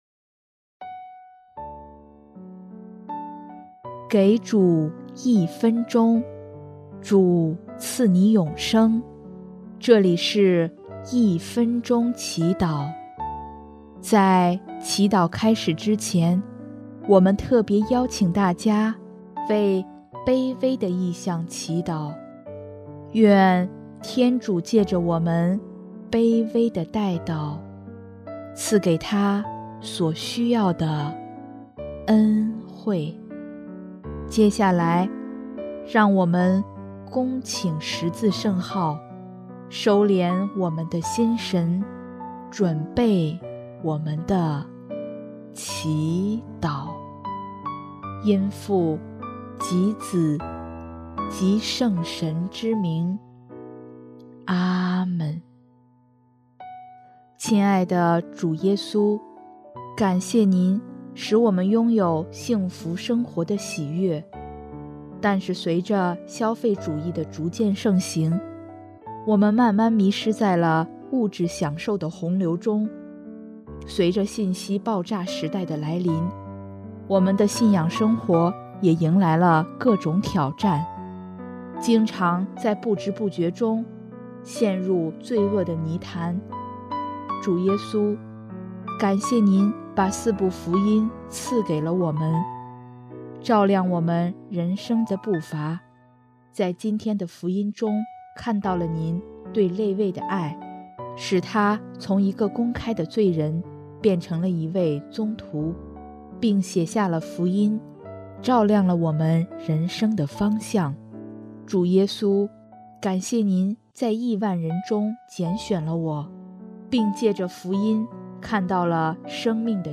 音乐：第四届华语圣歌大赛参赛歌曲《赞美阿爸》